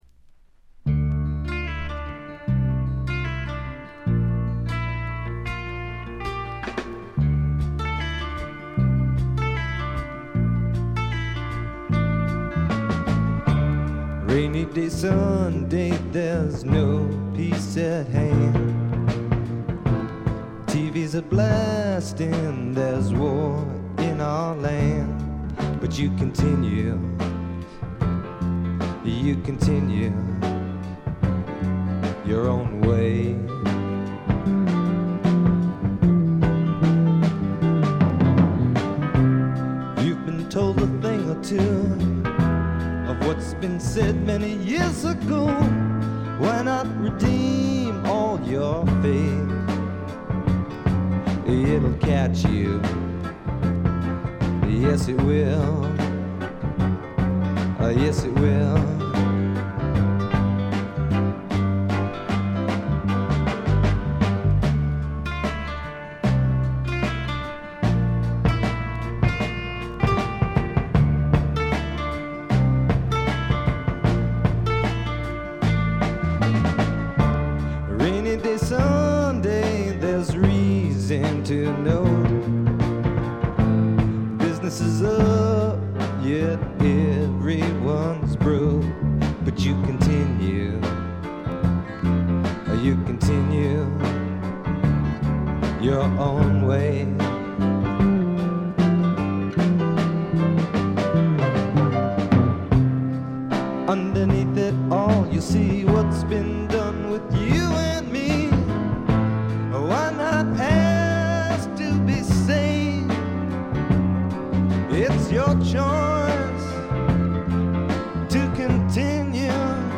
チリプチ少々、散発的なプツ音少し。
スワンプ／シンガーソングライター・ファンなら必携のスワンプ名作です。
試聴曲は現品からの取り込み音源です。